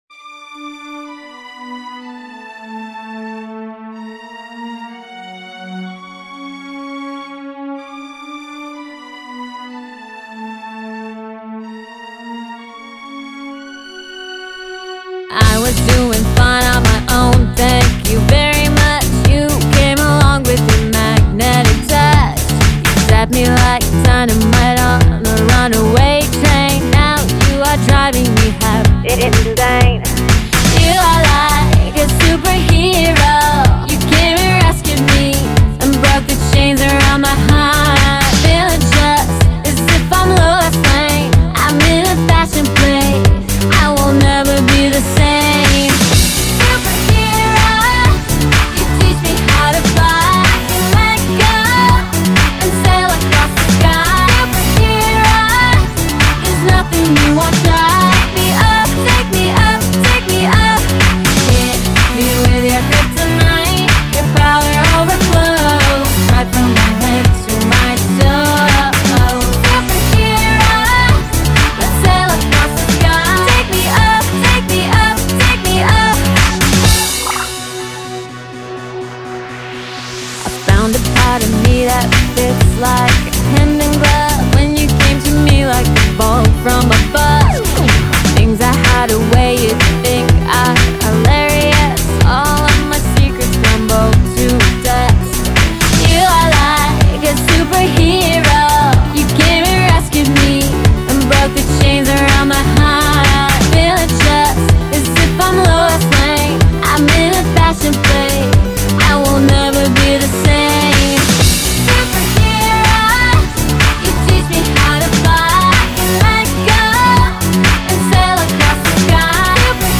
md/uptmpo dance